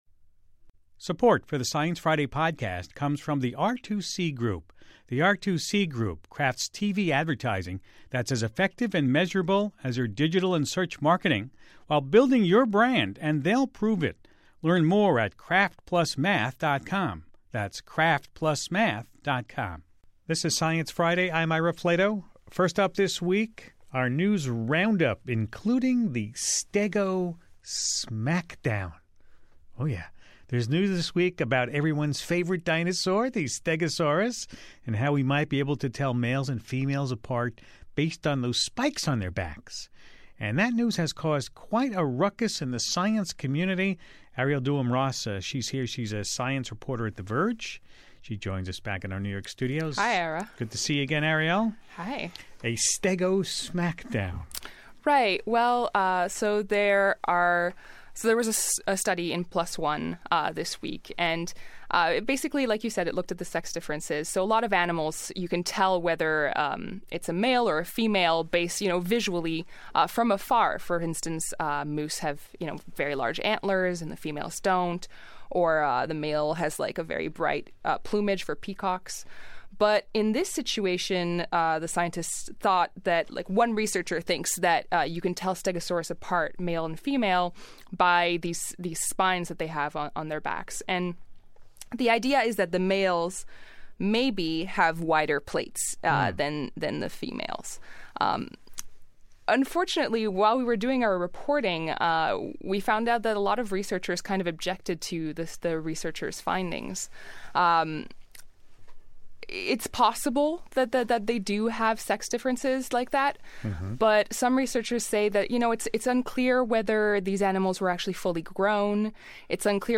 A look at the week in science, a graphic novel featuring pioneers of computing, a conversation with NASA administrator Charles Bolden, and a video about teaching evolution in the classroom.